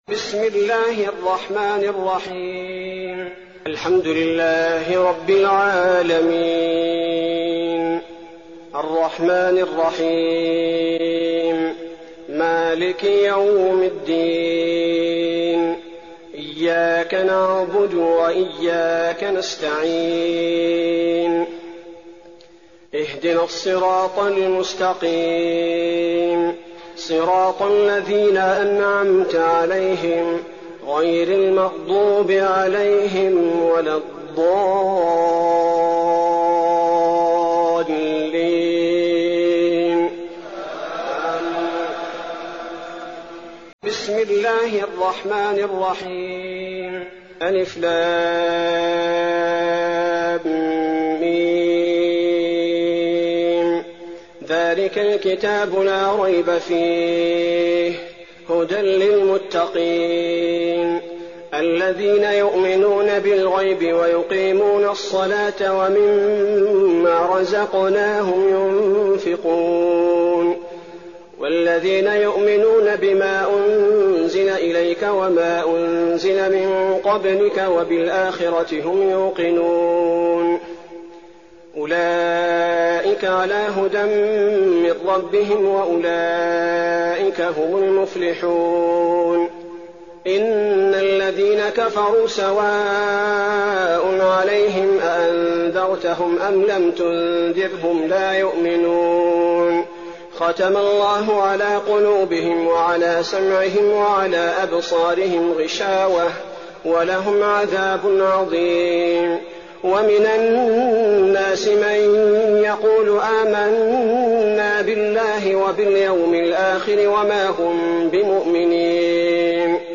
تراويح الليلة الأولى رمضان 1419هـ من سورة البقرة (1-74) Taraweeh 1st night Ramadan 1419H from Surah Al-Baqara > تراويح الحرم النبوي عام 1419 🕌 > التراويح - تلاوات الحرمين